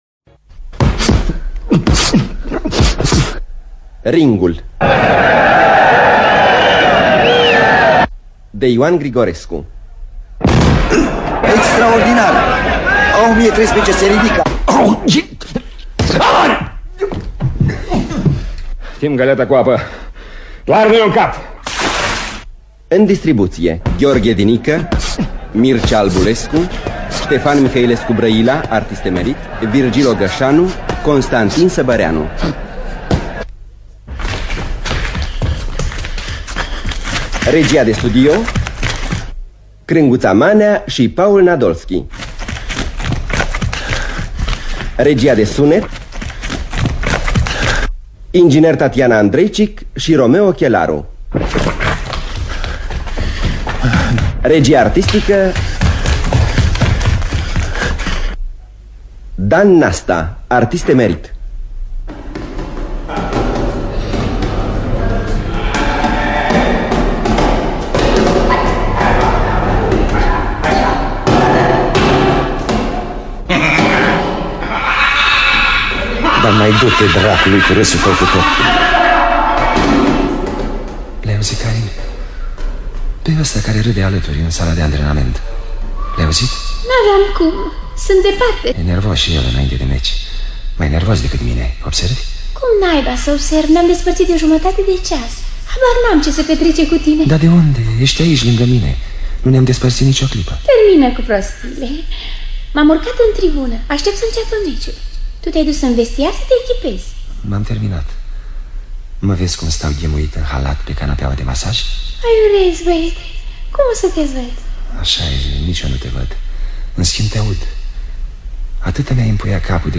Dramatizarea şi adaptarea radiofonică de Ilie Păunescu.